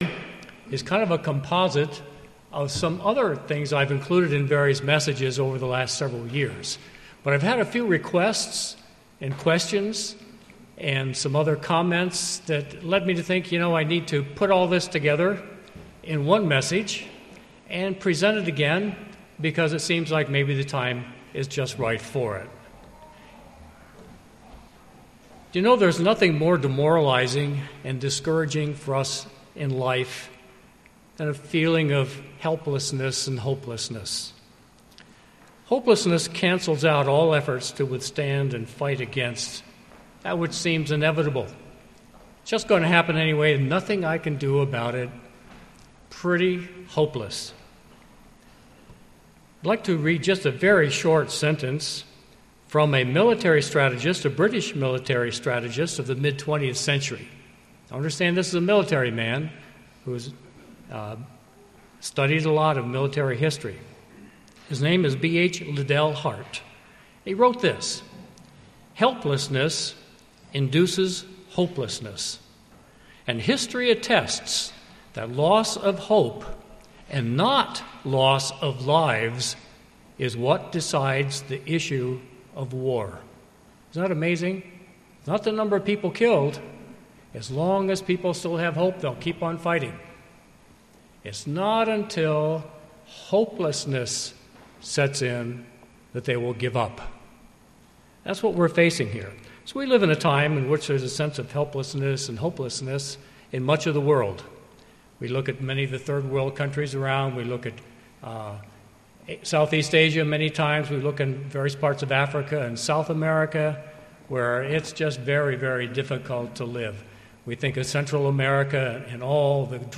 Given in Pittsburgh, PA
UCG Sermon Studying the bible?